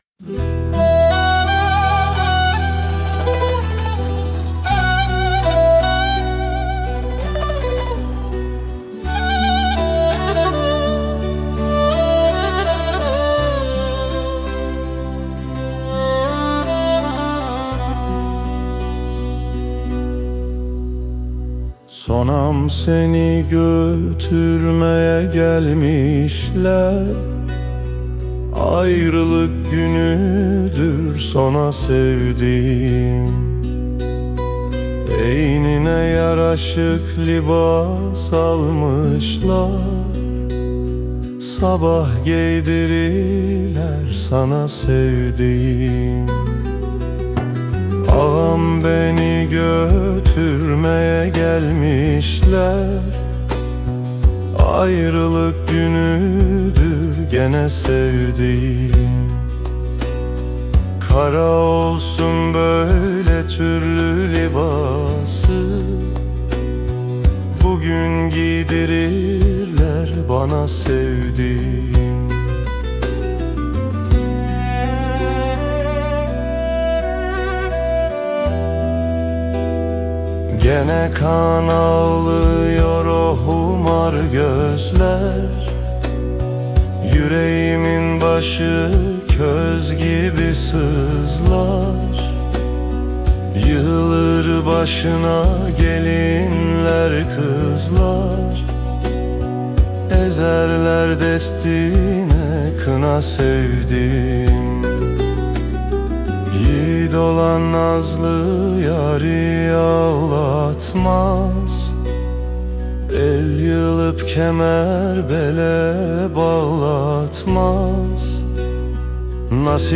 🎤 Vokalli 19.10.2025